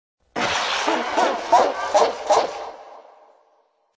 descargar sonido mp3 risa 2